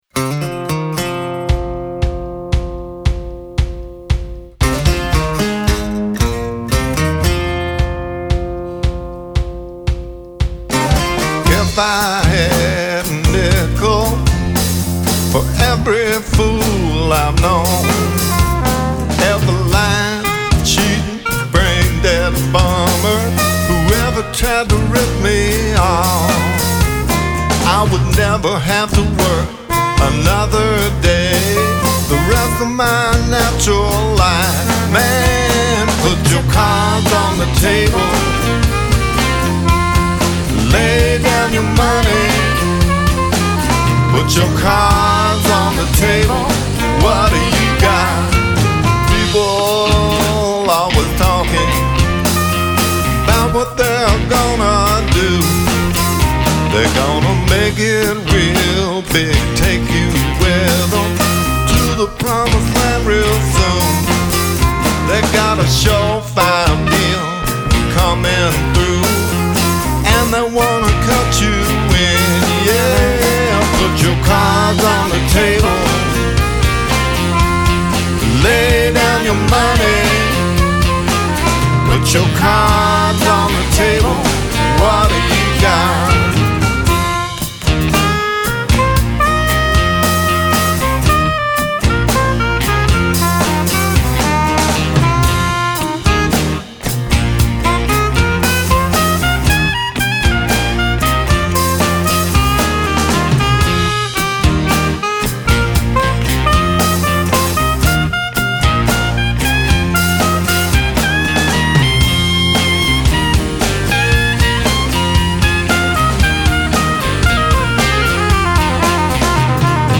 I’m posting audio here of “Cards on the Table”, which I just recorded with a little help from my friends.